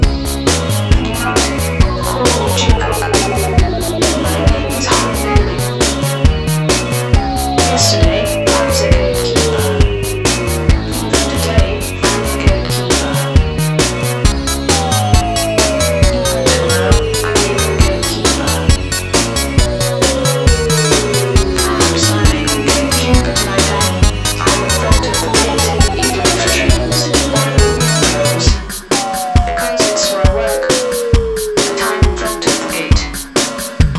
English spoken-word section
Beyond that, it gets a little hard to hear.